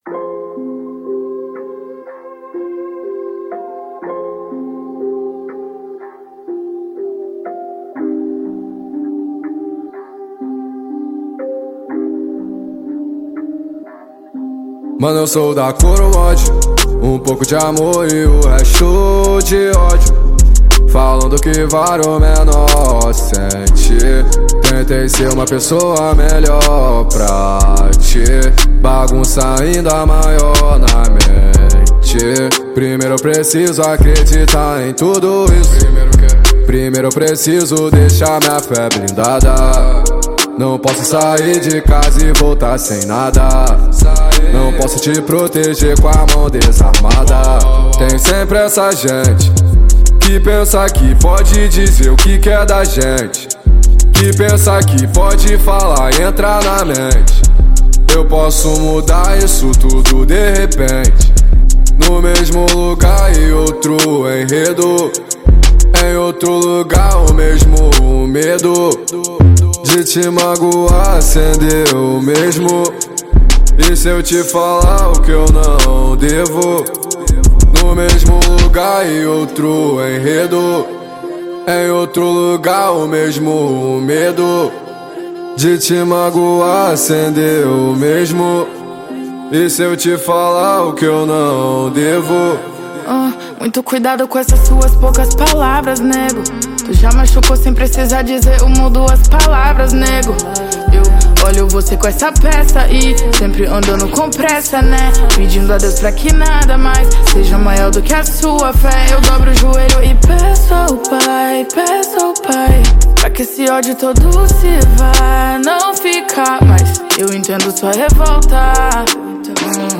2025-03-19 01:43:15 Gênero: Trap Views